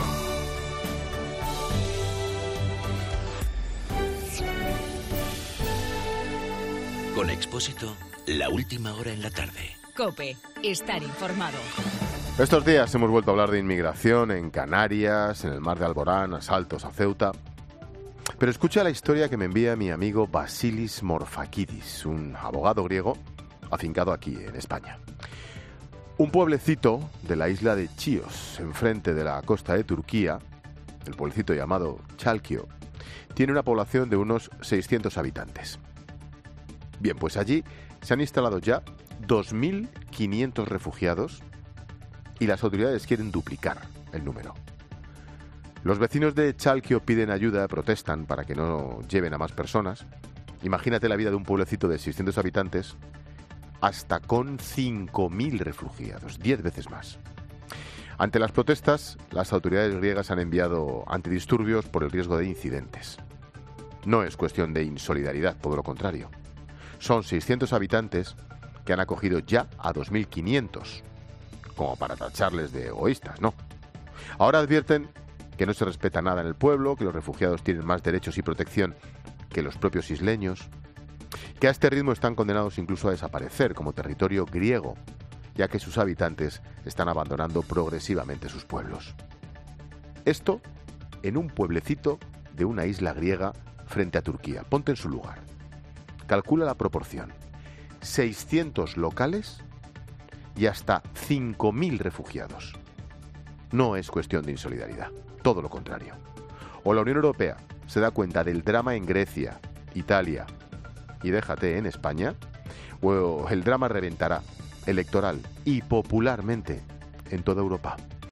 AUDIO: El comentario de Ángel Expósito a raíz de la situación en la isla de Chios, en el pueblo de Chalkio.